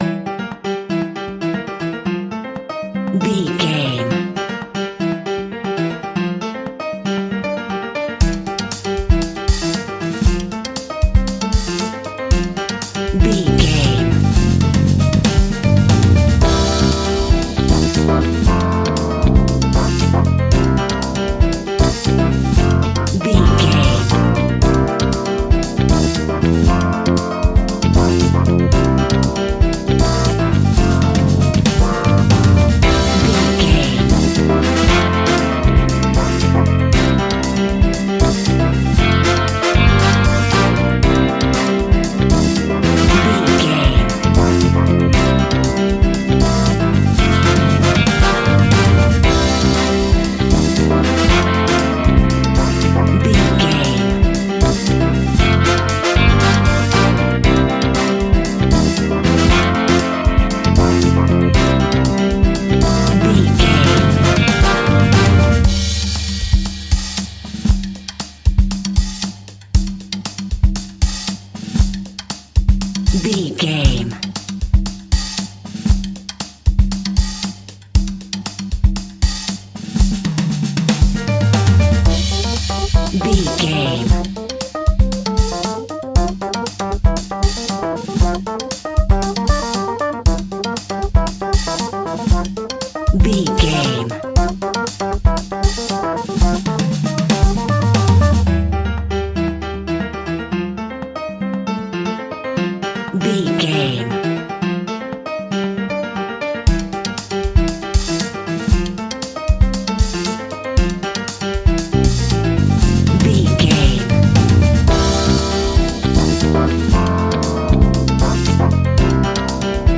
Ionian/Major
flamenco
maracas
percussion spanish guitar
latin guitar